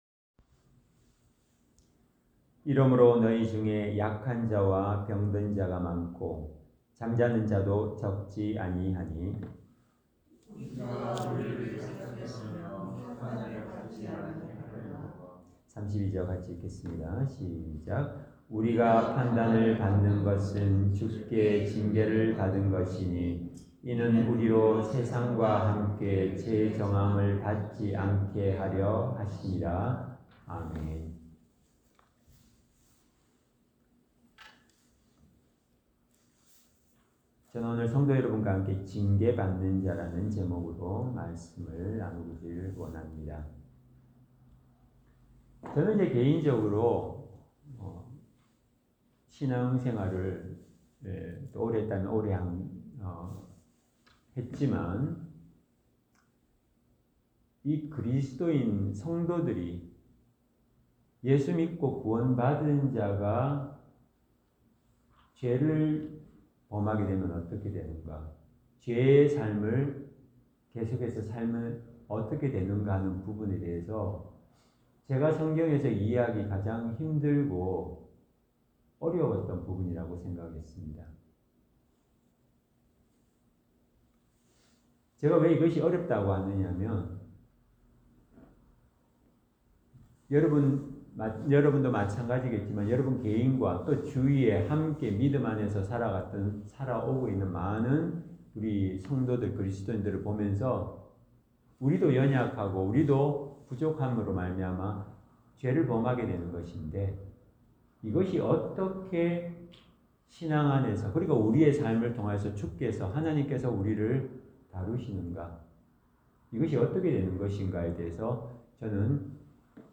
Service Type: 주일 예배